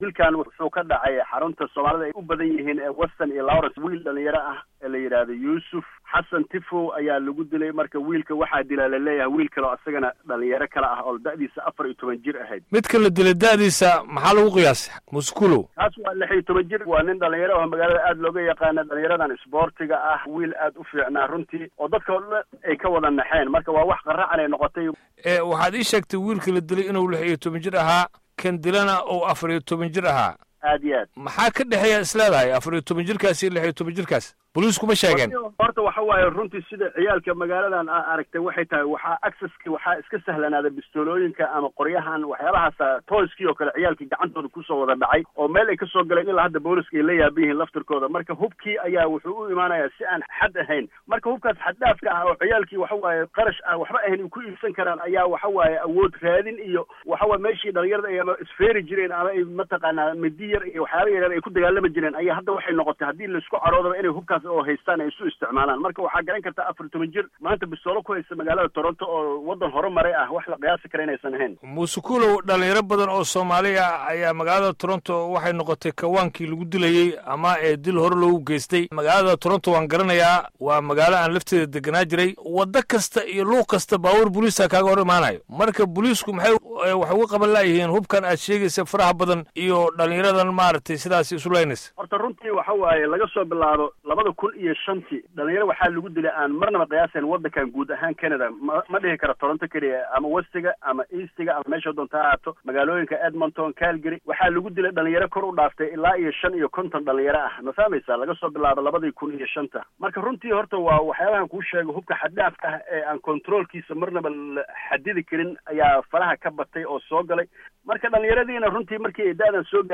Waraysi: Dilalka u dhexeeya dhalinyarada Soomaalida ee Canada